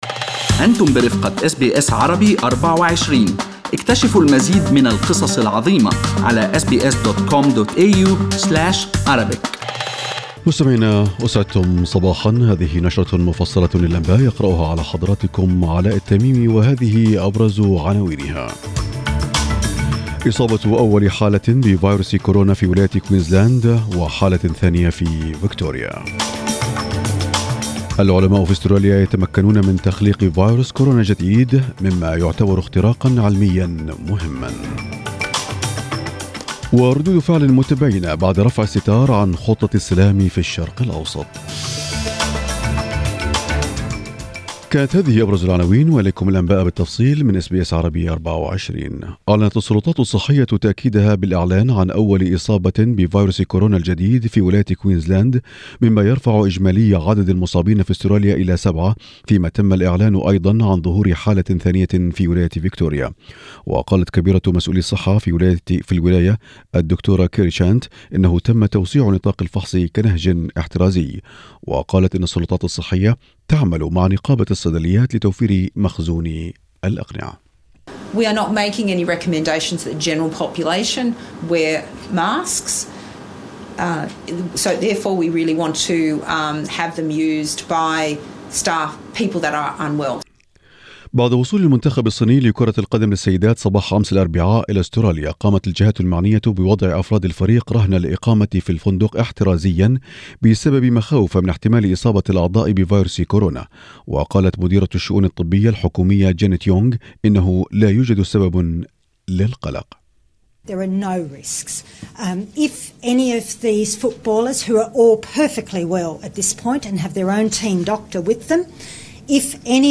نشرة أخبار الصباح 30/01/2020
Arabic News Bulletin Source: SBS Arabic24